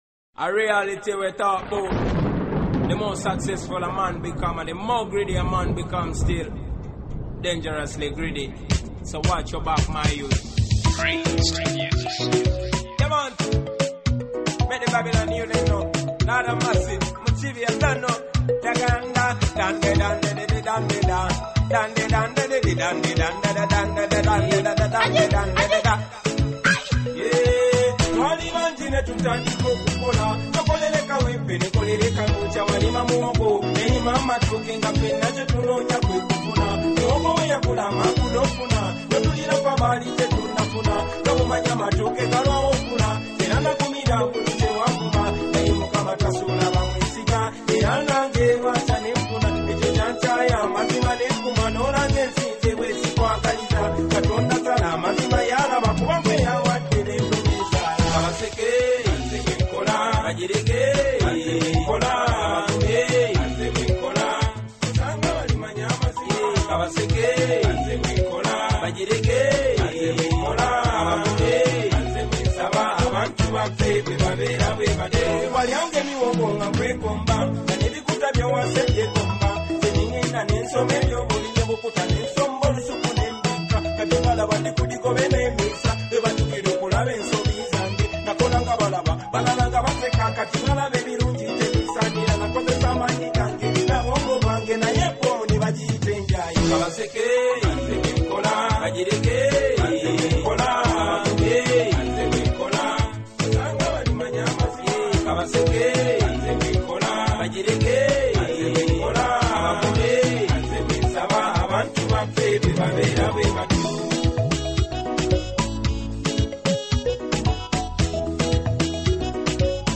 Genre: Oldies